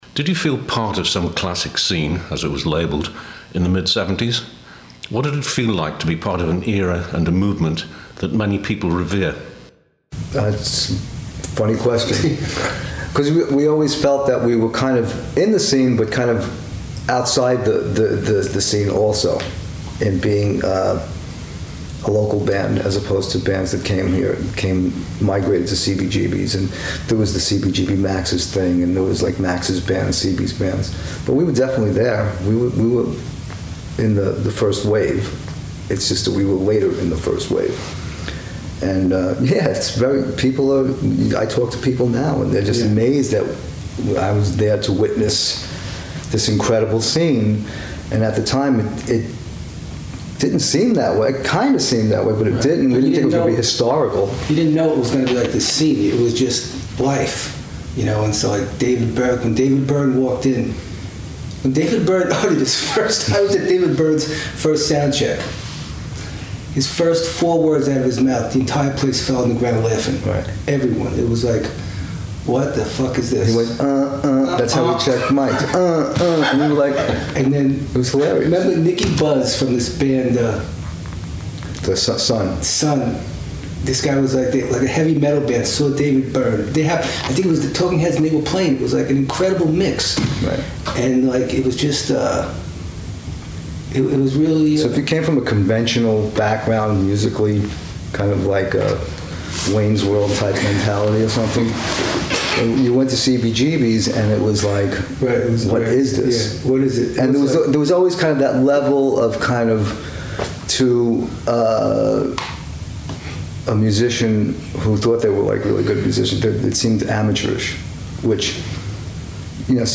The Shirts in interview
They spoke fast, since the rehearsal followed with the new, expanded seven-piece line-up shortly after, getting ready for performance at CBGB on Saturday May 31 2003.